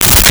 Space Gun 15
Space Gun 15.wav